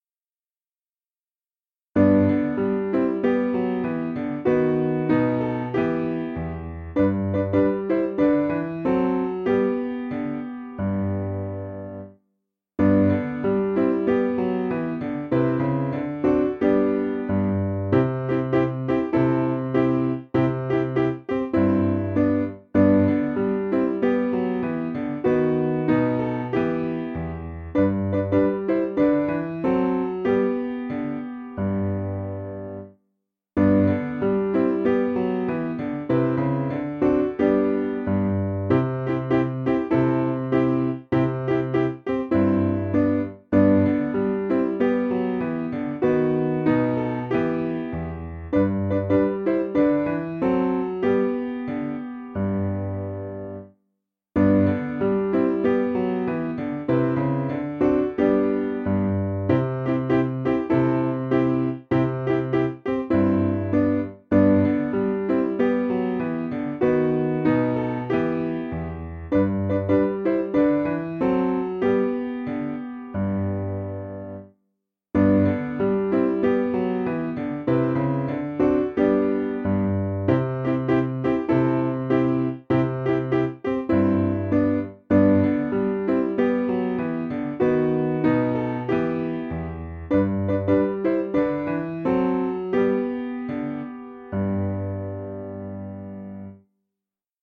Simple Piano
(CM)   4/G